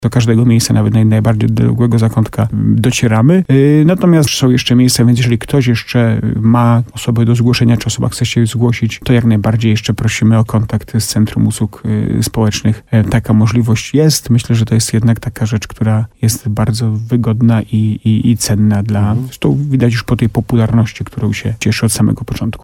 W programie Słowo za Słowo w radiu RDN Nowy Sącz burmistrz Starego Sącza Jacek Lelek zachęcał do zgłaszania potrzebujących.